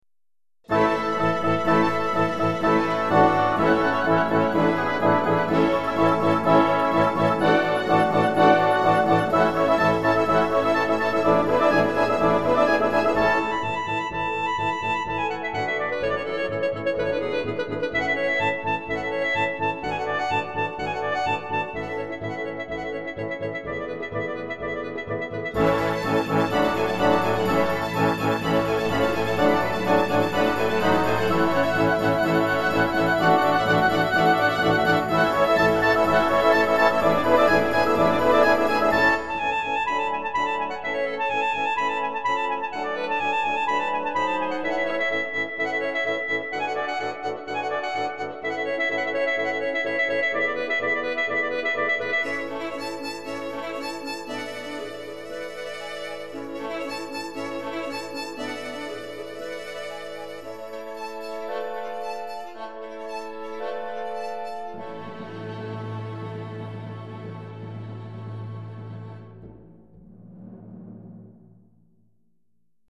აი ეს არის უკვე გაცოცხლებული *.mid ფაილი (თრაილერია).